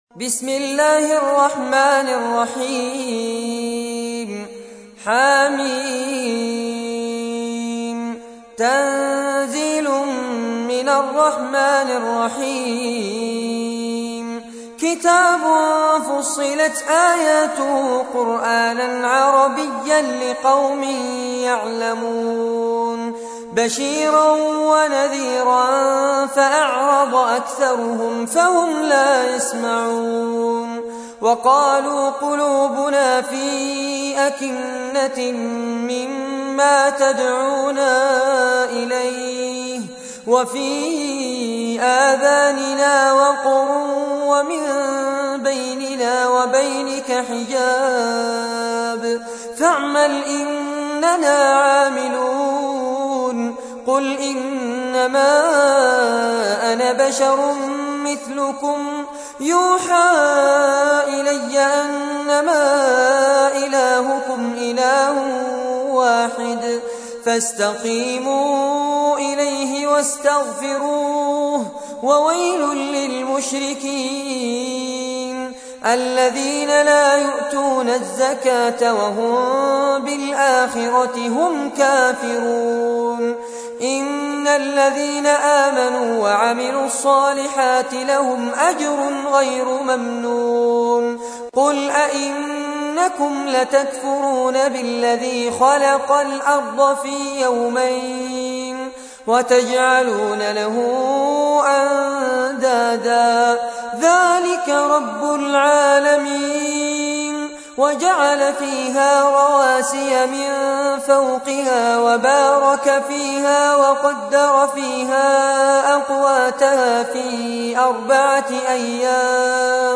تحميل : 41. سورة فصلت / القارئ فارس عباد / القرآن الكريم / موقع يا حسين